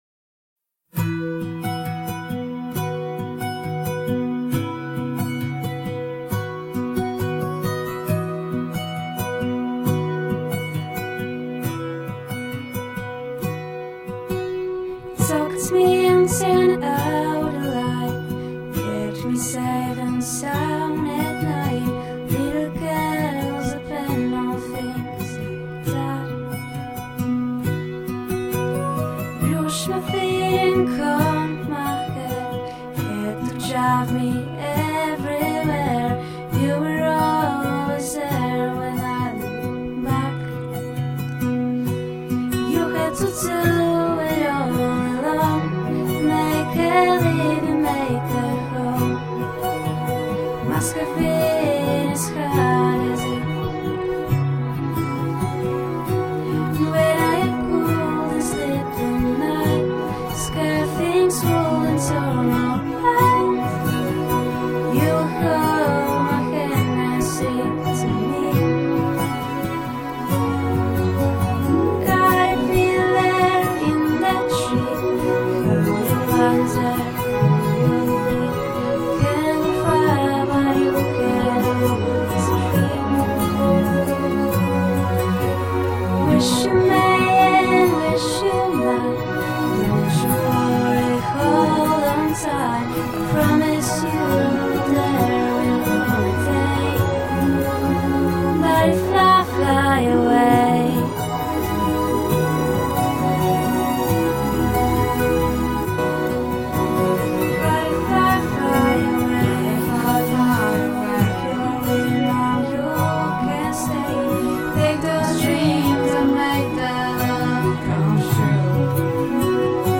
Kolędy:
Podsumowanie przedstawienia jasełkowego, które uczniowie naszego gimnazjum przygotowali z okazji Świąt Bożego Narodzenia 2010.
Kolęda